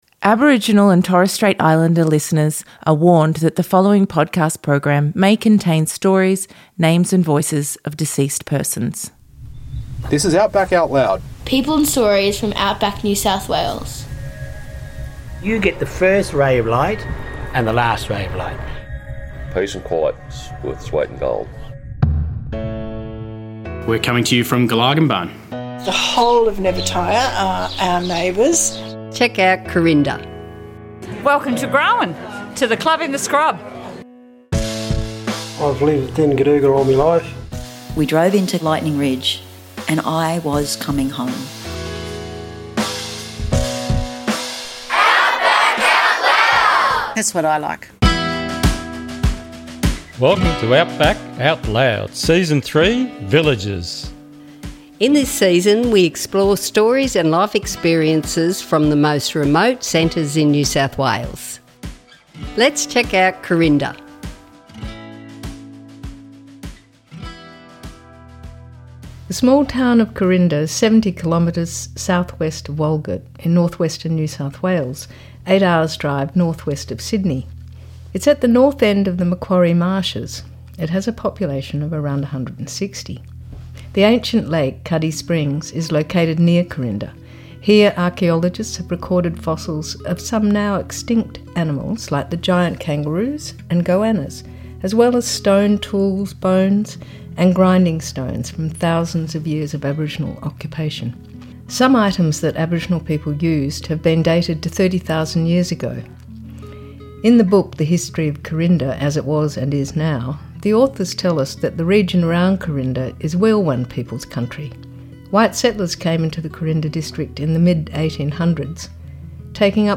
In this Episode we hear interviews from people in Carinda NSW, Weilwan Country. This episode features stories, memories and reflections about Carinda.